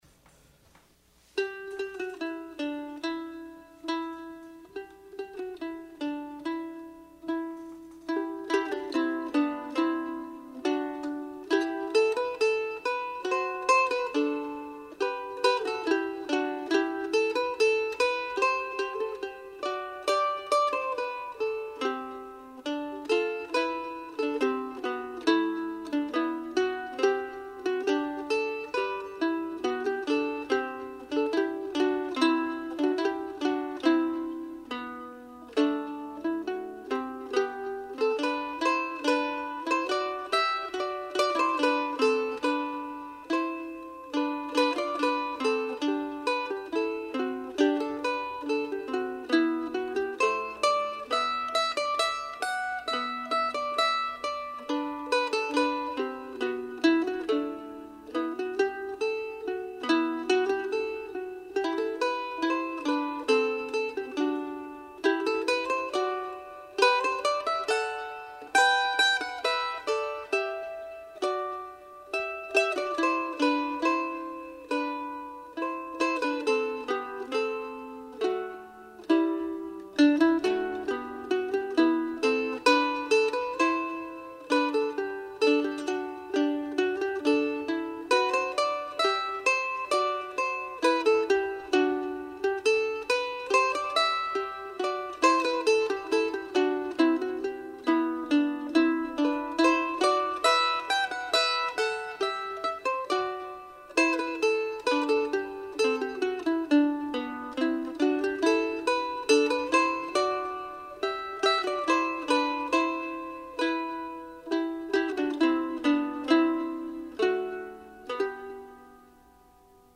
Here are two simple duets for two mandolins (or any other instruments with similar ranges) that I wrote down sometime around 1980.
The challenge in playing these short tunes is to keep them from sounding mechanical and to allow them to breathe a little.